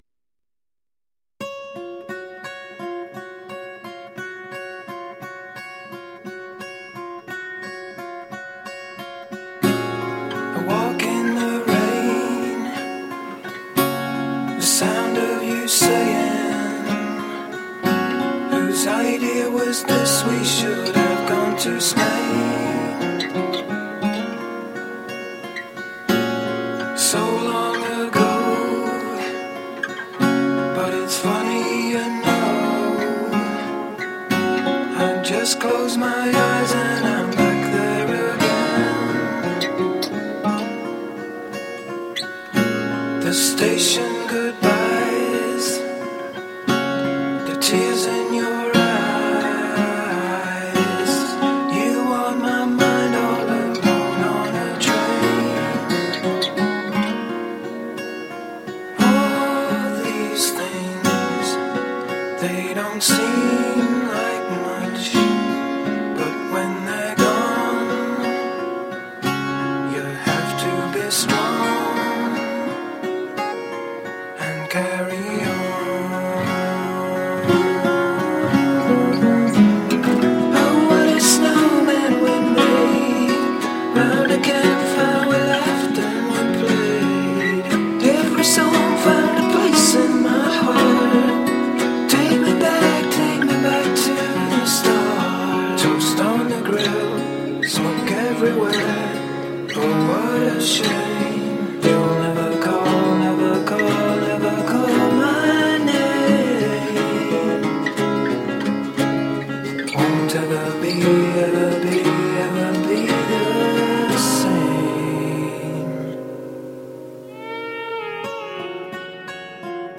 Soul soothing acoustic guitar songs.